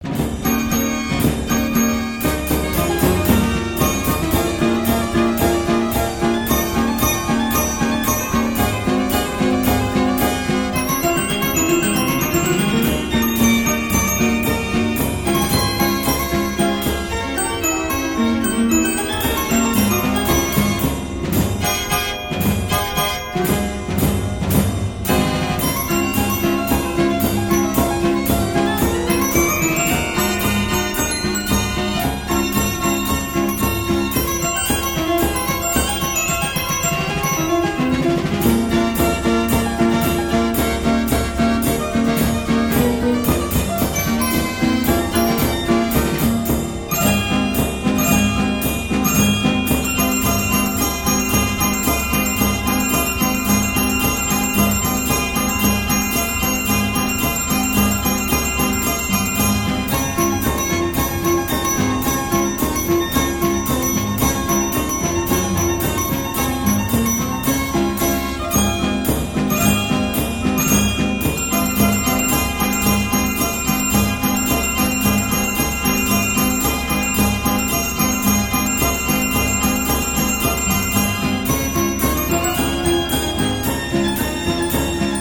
NICKELODEONS!